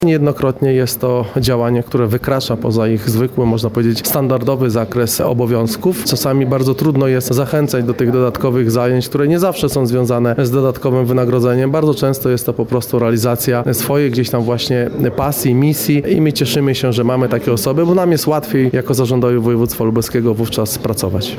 O tym, jakie działania decydowały o wyróżnieniu, mówi więcej Marcin Szewczak, członek Zarządu Województwa Lubelskiego: